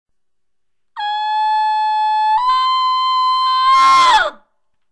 Bull Elk Sounds The Bull Elk make the most magnificent and powerful sounds.
Two Tone
two_tone.wma